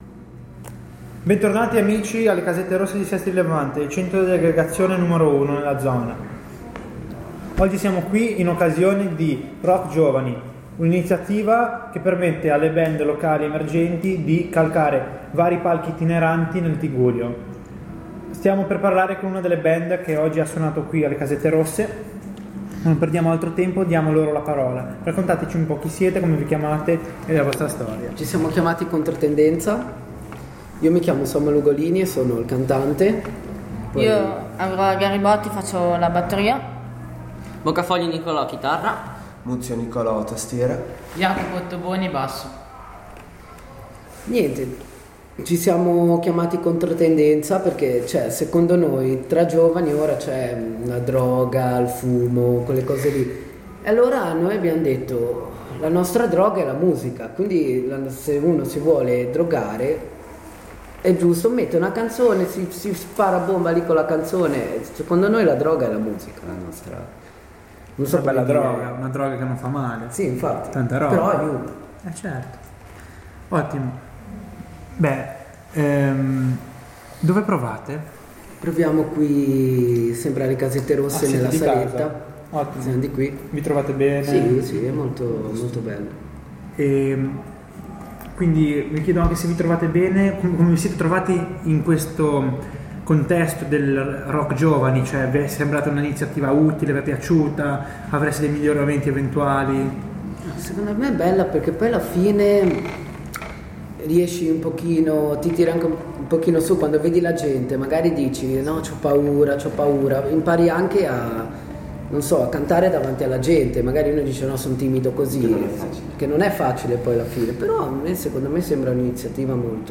Intervista a Controtendenza